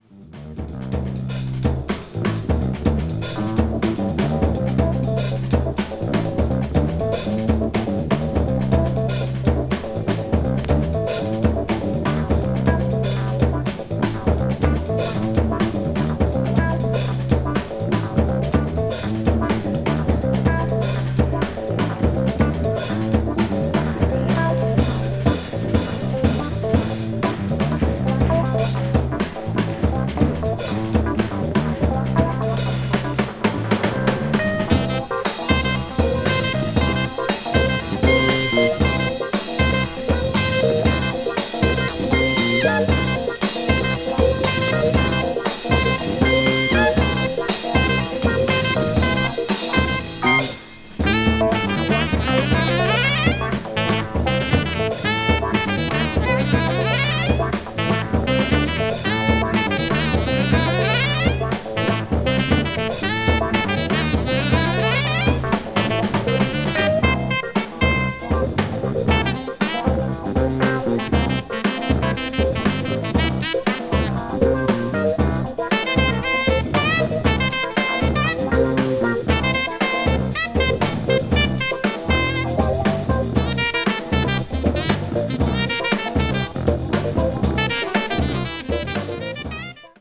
saxophonist
recorded live at the Montreux Jazz Festival in Switzerland